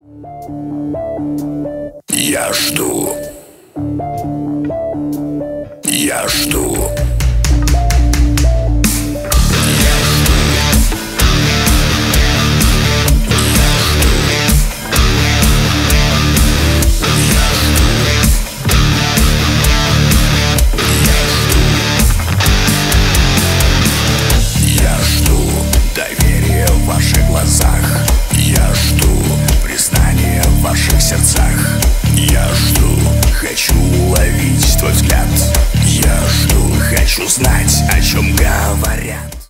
Рок Металл
кавер # громкие